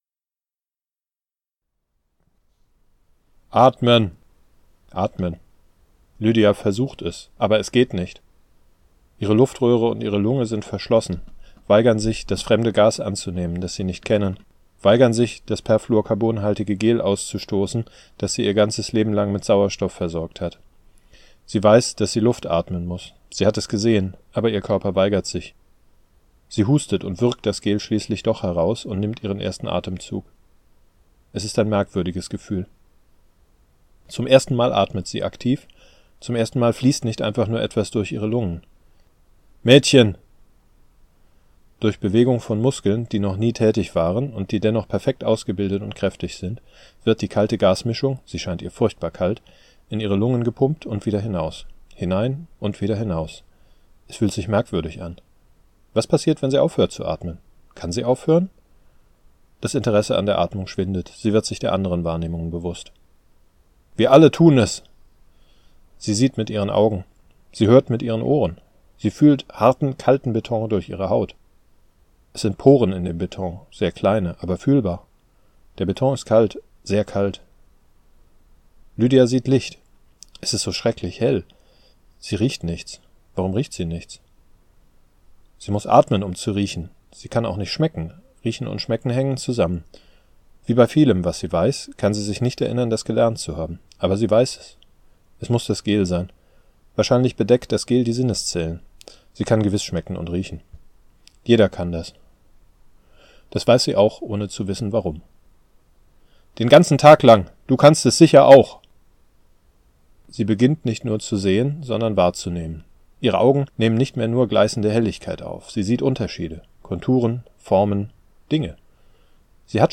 Eine Leseprobe findest du hier , und für den Fall, dass du Proben lieber hörst, hab ich für dich mal reingelesen.
lets-read-des-menschen-bester-freund-seric3b6s-diesmal-wirklich.mp3